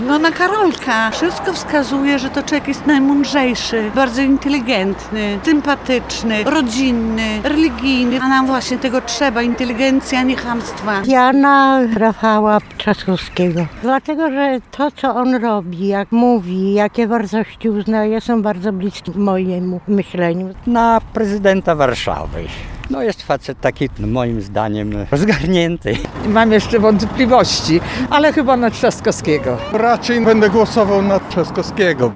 Rafała Trzaskowskiego najczęściej wymieniali przechodnie spotkani na ulicach Suwałk, których pytaliśmy, kogo poprą w wyborach prezydenckich.